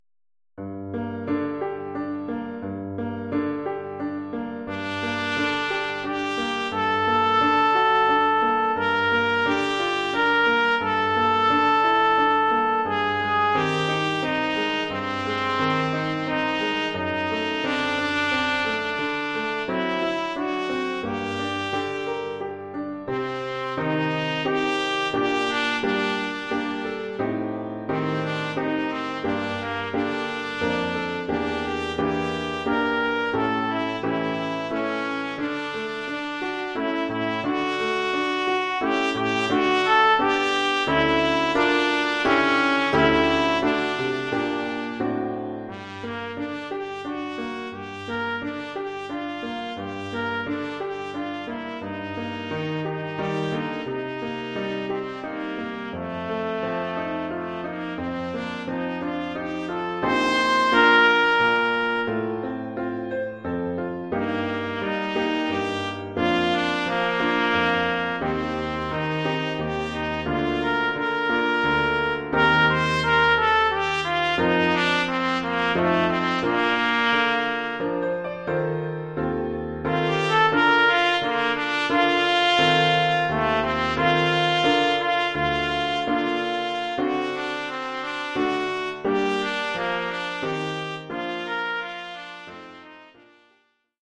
Formule instrumentale : Trompette et piano
Oeuvre pour trompette ou cornet
ou bugle et piano..